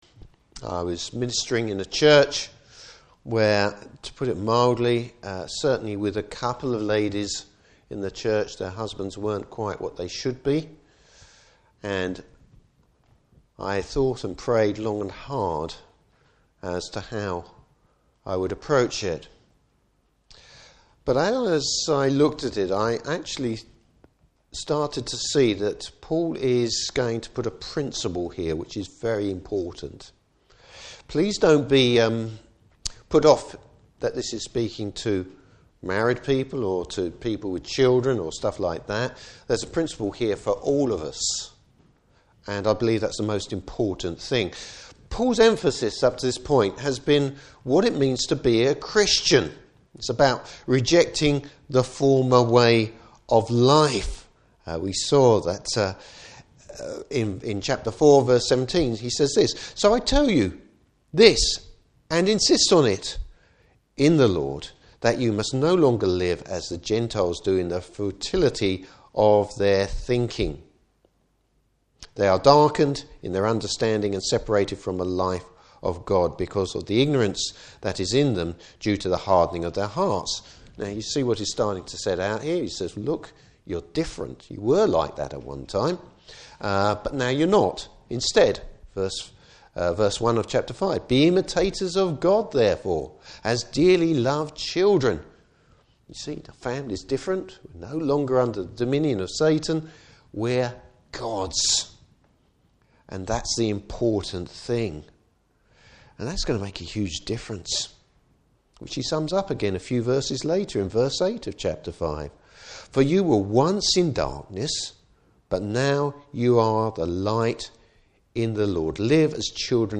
Service Type: Morning Service Paul highlights an overriding principal for Christians.